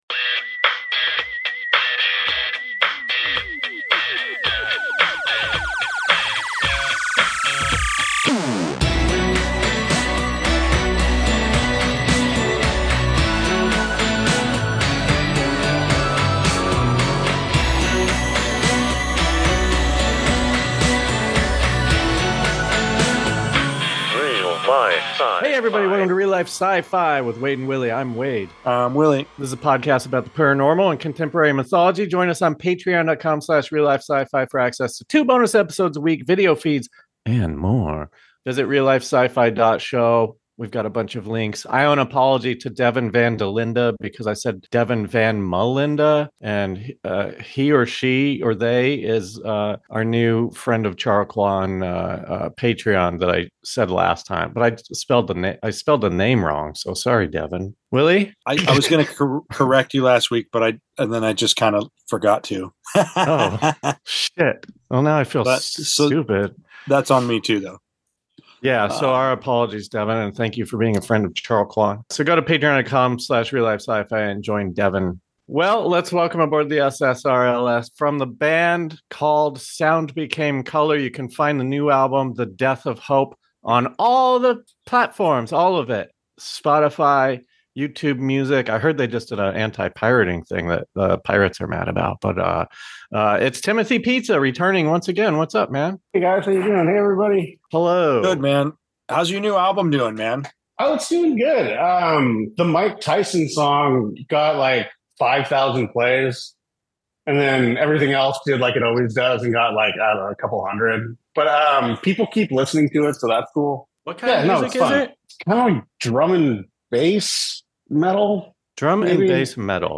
An interview with a reptilian from earth (not an alien) - the Lacerta files are available online. A Swedish de-bunker asks a Terran questions in an attempt to find the truth.